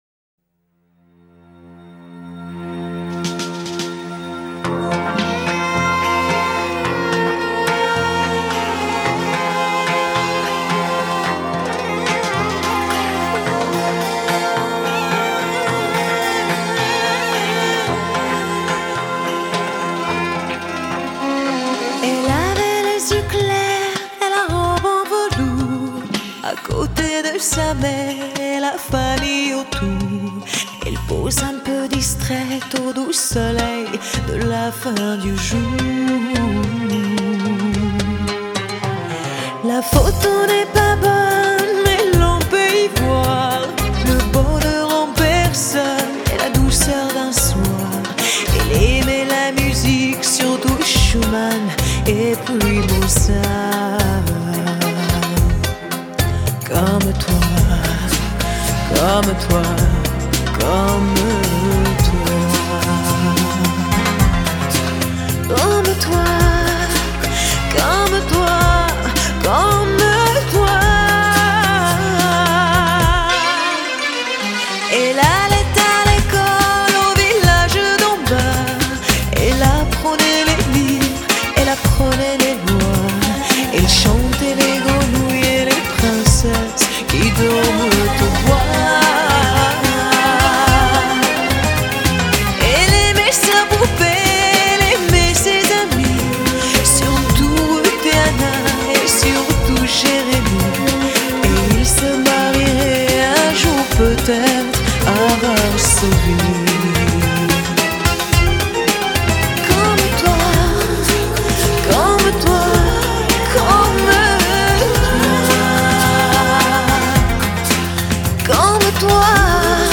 Quelle voix bouleversante...